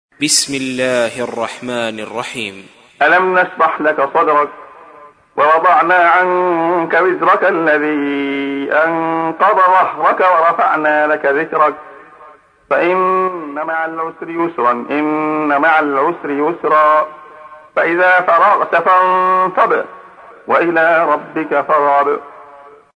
تحميل : 94. سورة الشرح / القارئ عبد الله خياط / القرآن الكريم / موقع يا حسين